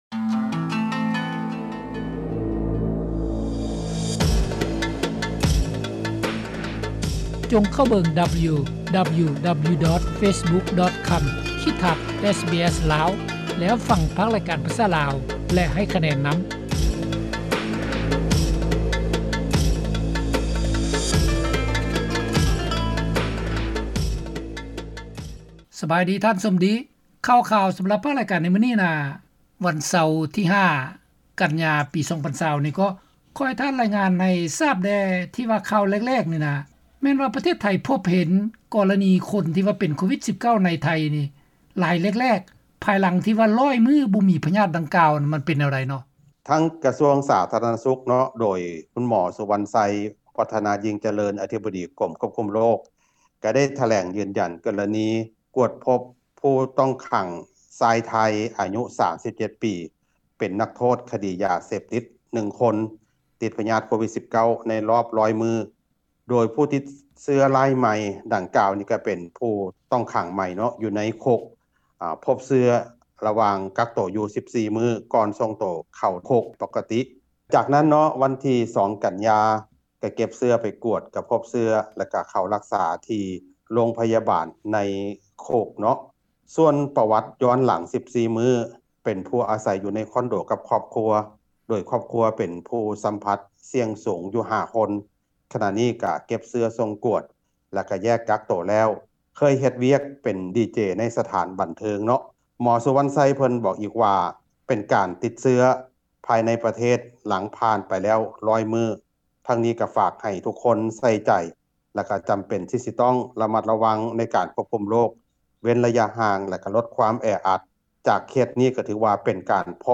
lao_5_sept_part_11_mekhong_report.mp3